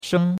sheng1.mp3